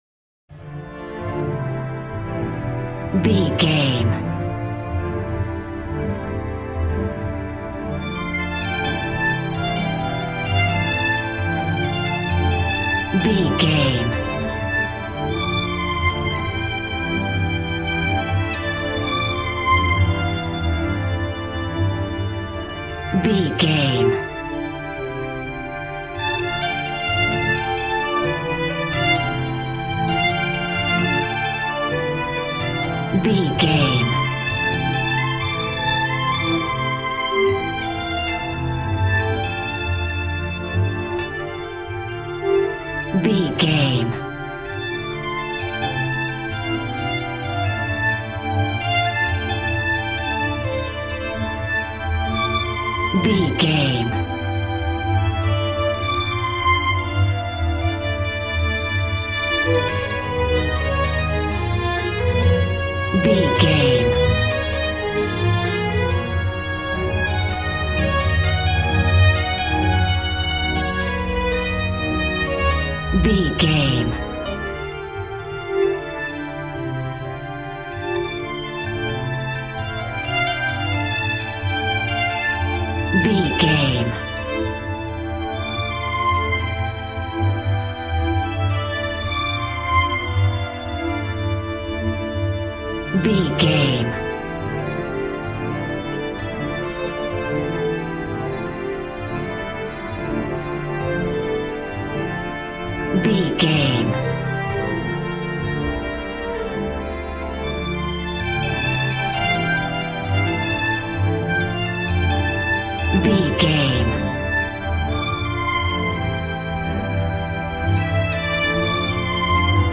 Aeolian/Minor
joyful
conga